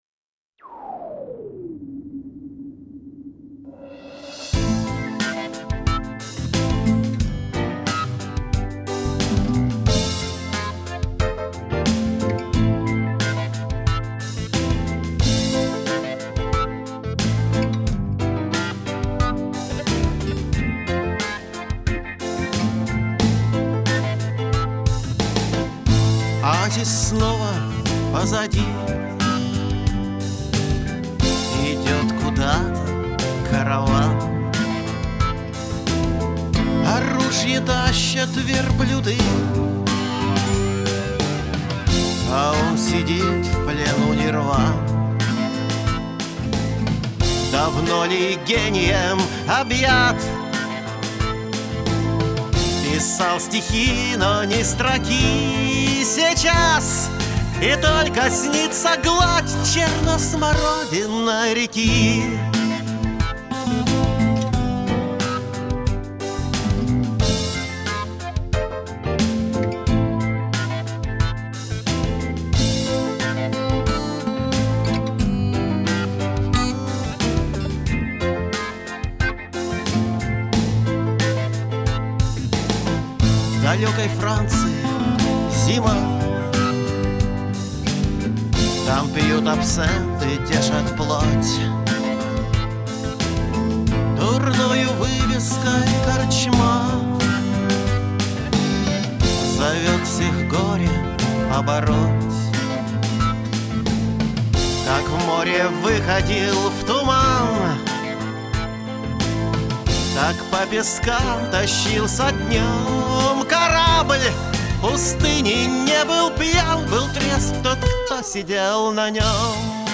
Бардрок (4123)